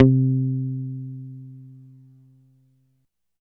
85 BASS C4.wav